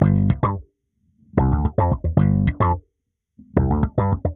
Index of /musicradar/dusty-funk-samples/Bass/110bpm